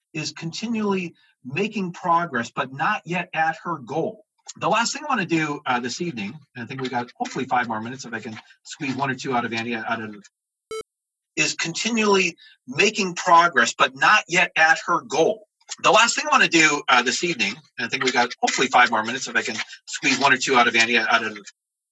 Help with fixing tinny/boxy/muddy audio talk
I’m not sure if it’s muddy or boxy or neither - but it sounds kinda muffled. It also sounds tinny to me.
Cut as much bass as is tolerable, as the roomy/boxy reverberation is mostly in the bass.